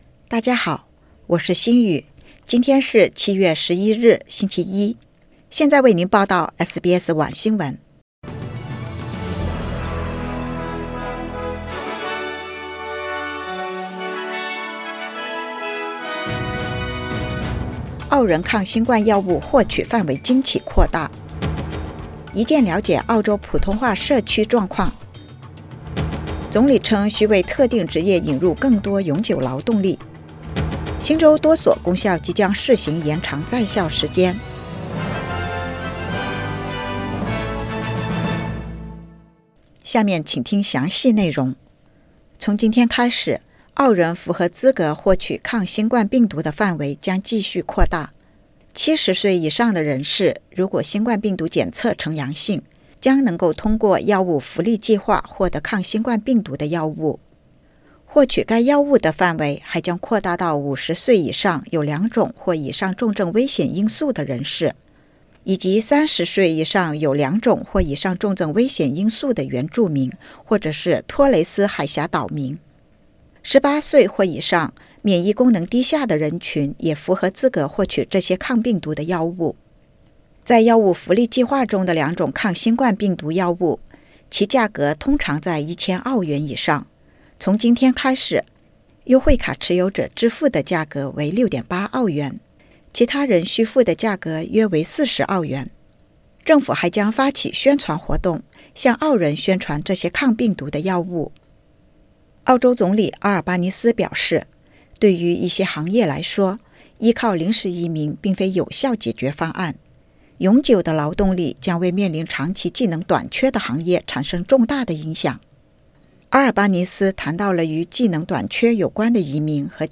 SBS晚新闻（2022年7月11日）
SBS Mandarin evening news Source: Getty Images